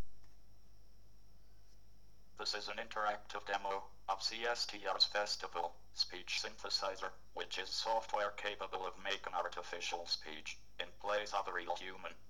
It's a Speech Synthesis System, allowing you to convert text to speech.
You can feed the Festival sound to your sound recording software to create audio files, which you can later process as you see fit.
Here's a 12-second sample recorded in this fashion.